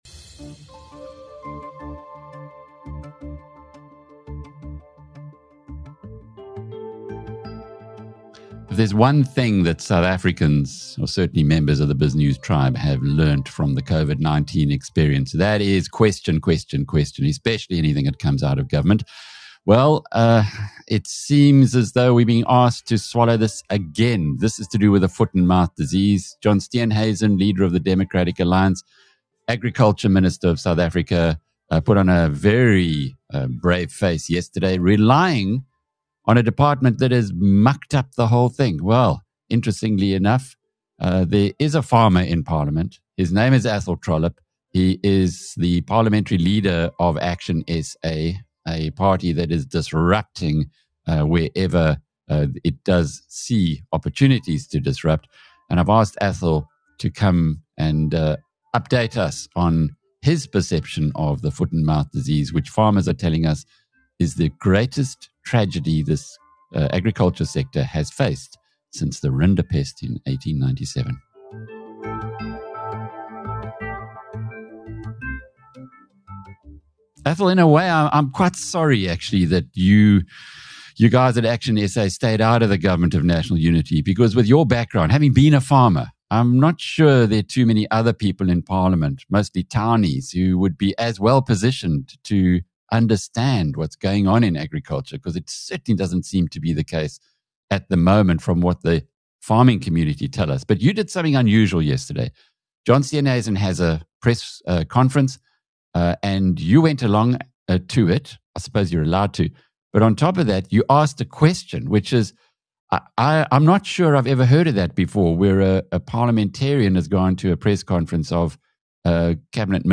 From dairy farms to feedlots, South Africa’s agricultural economy is under siege. Athol Trollip outlines how foot-and-mouth disease has crushed production, frozen incomes and exposed deep failures inside the Department of Agriculture. A conversation every business leader and taxpayer should hear.